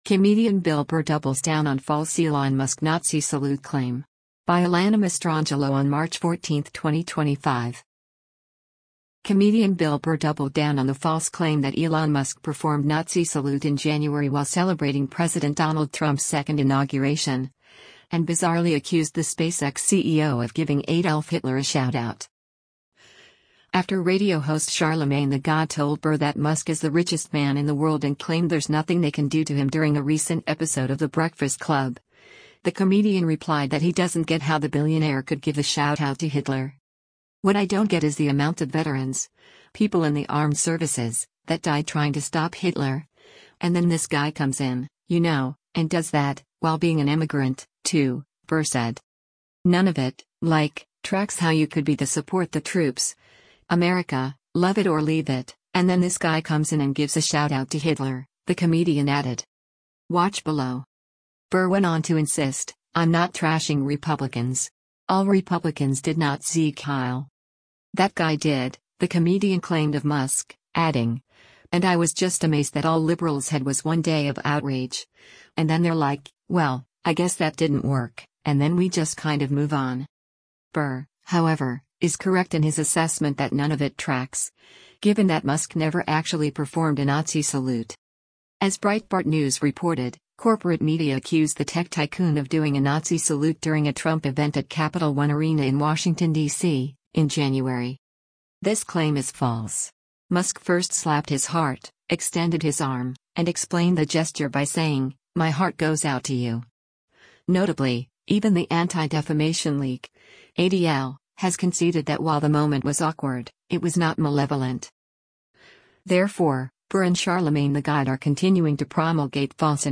After radio host Charlamagne Tha God told Burr that Musk is “the richest man in the world” and claimed “there’s nothing they can do to him” during a recent episode of The Breakfast Club, the comedian replied that he doesn’t “get” how the billionaire could “give a shoutout to Hitler.”